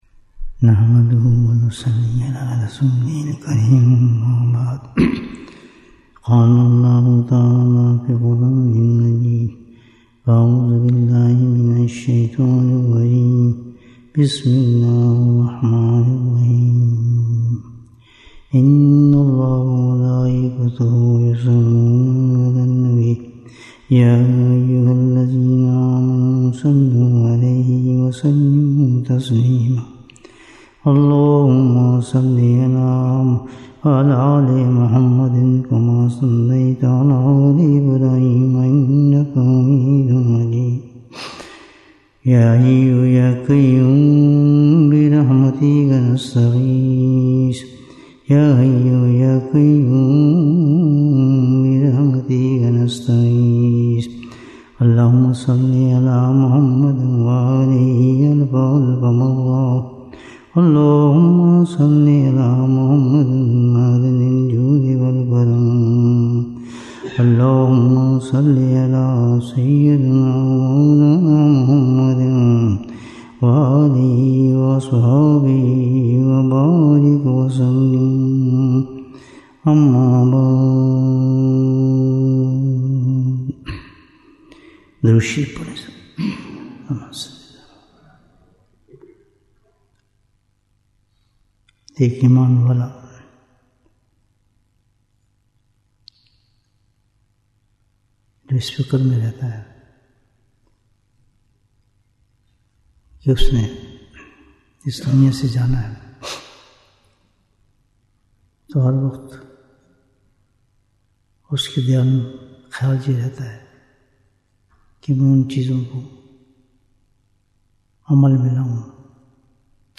Bayan, 79 minutes 20th February, 2025 Click for English Download Audio Comments What is the Biggest Obstacle in the Path of Allah?